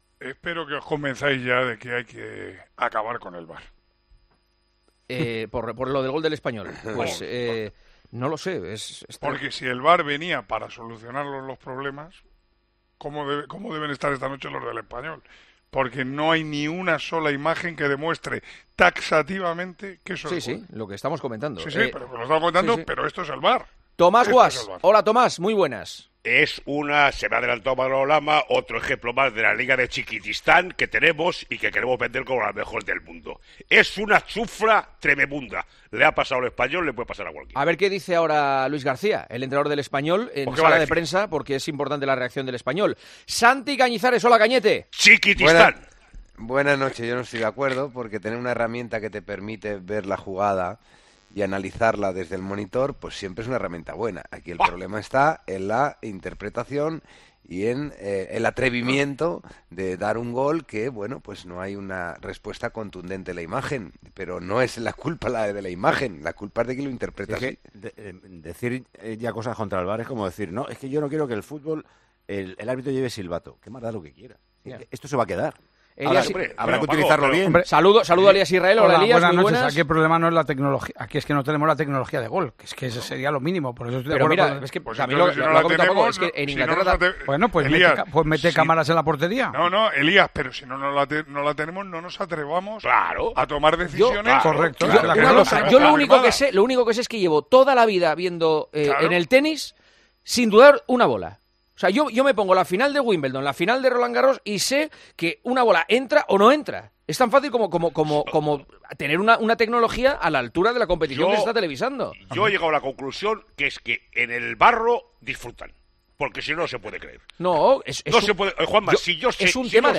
AUDIO: El tertuliano de El Partidazo de COPE se mostró en contra del videoarbitraje tras el polémico gol durante el partido de este miércoles.